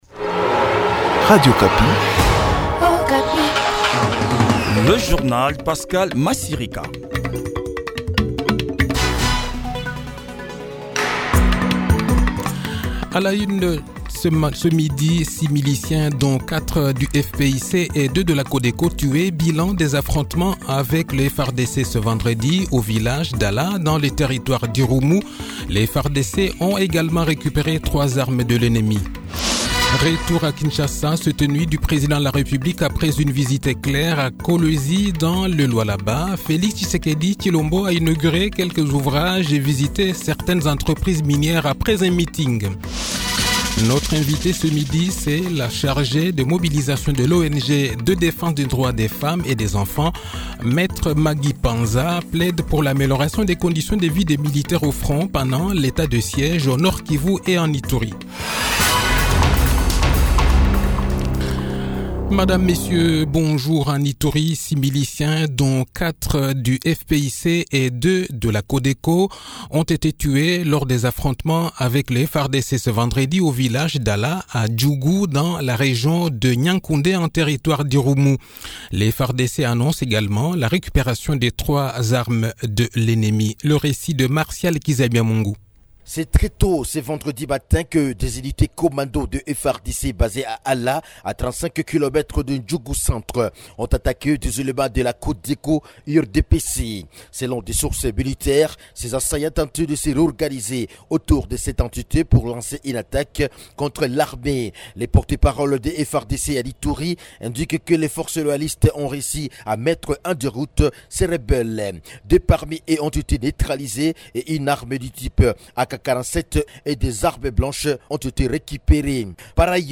Le journal-Français-Midi